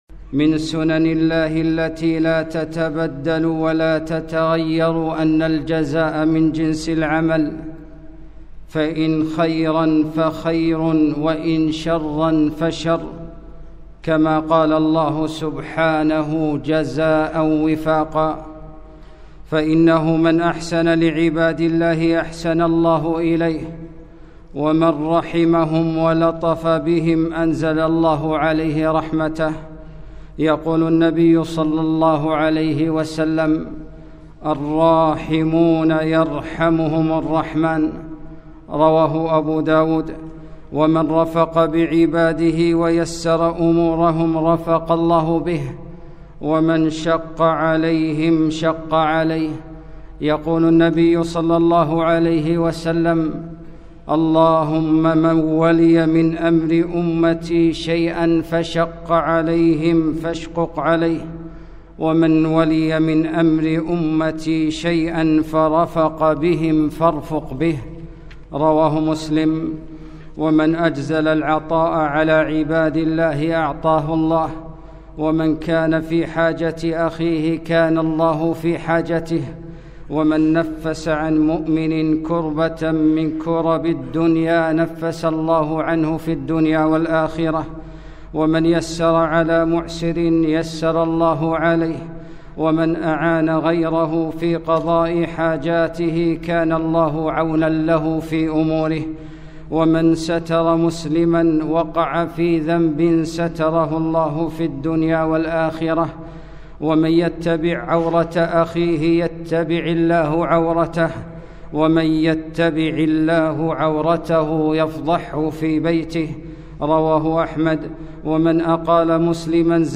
خطبة - جزاءٍ وفاقًا